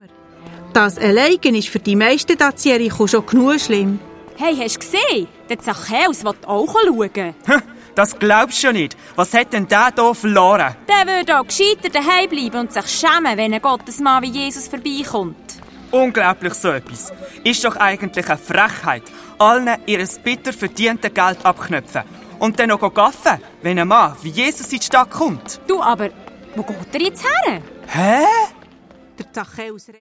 Zusätzlich enthalten: Mehrere Songs, das Hörspiel „D’Chatz im Chäller“ der lustigen Bärenkinder der Adonia-KidsParty
Hörspiel-Album
Auf jeder CD finden Sie zudem mehrere Songs und ein Hörspiel, das ein Gedanke aus den Geschichten in die heutige Zeit überträgt.